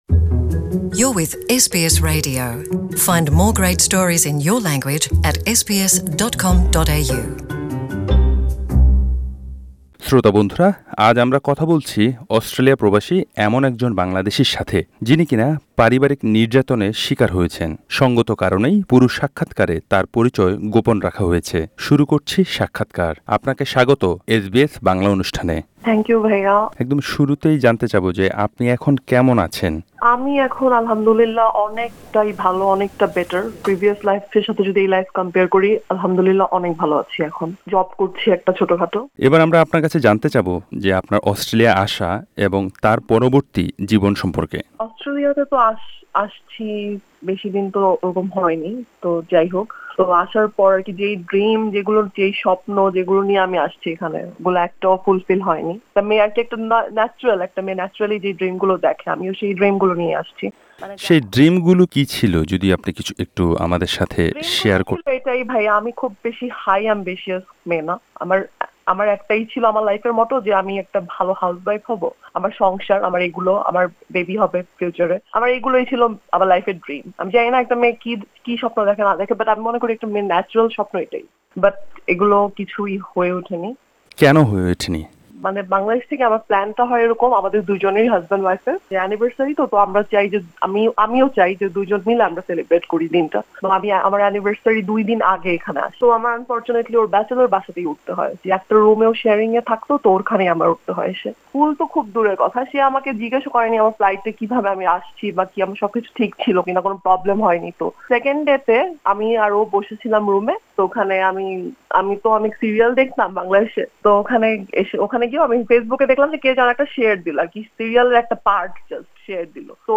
এয়ারপোর্ট থেকেই শুরু হয় তার মানসিক নির্যাতন। অস্ট্রেলিয়া আসার দ্বিতীয় দিনের মাথায় শিকার হন শারীরিক নির্যাতনের। পারিবারিক নির্যাতনের শিকার অস্ট্রেলিয়া প্রবাসী এক বাংলাদেশী নারীর সাথে কথা বলেছে এসবিএস বাংলা।
তার কন্ঠস্বরেও আনা হয়েছে কিছুটা পরিবর্তন।
নির্যাতনের এক রাতের কথা বলতে গিয়ে কেঁদে ফেলেন তিনি।